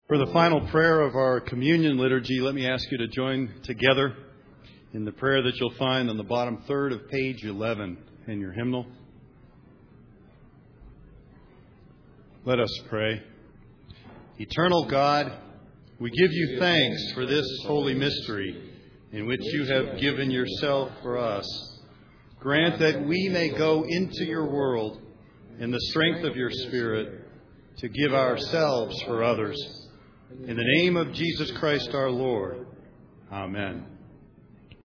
Palm Sunday Worship Service
Closing Communion Prayer                                                                 Pastor